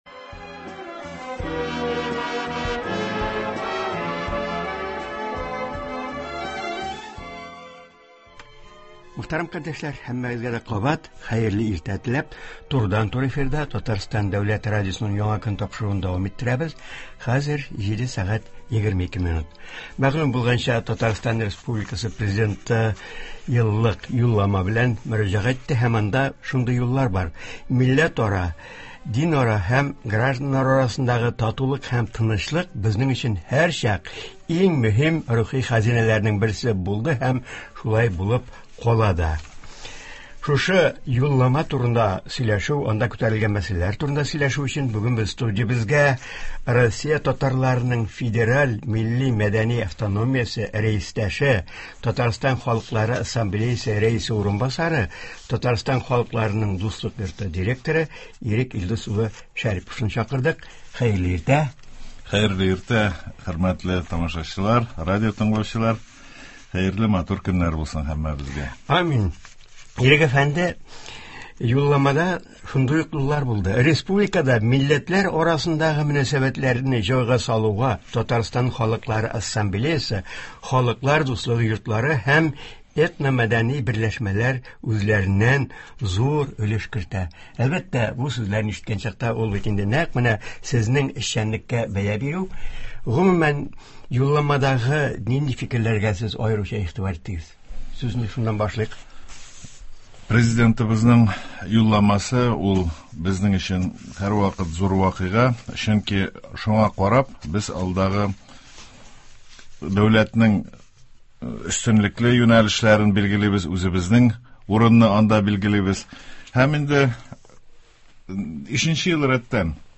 турыдан-туры эфирда